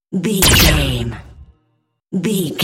Dramatic stab laser shot deep energy
Sound Effects
Atonal
heavy
intense
dark
aggressive
hits